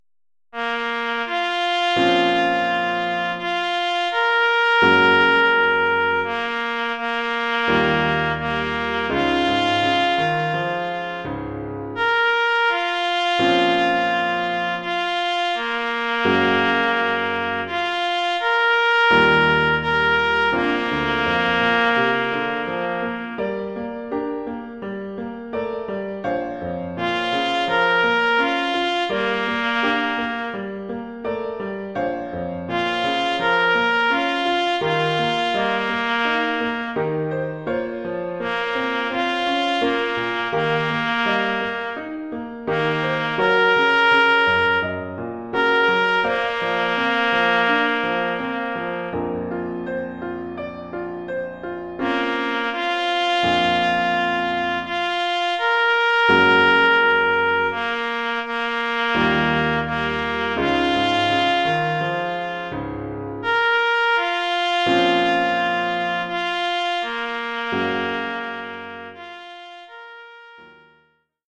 Formule instrumentale : Clairon ou clairon basse et piano
clairon basse et piano.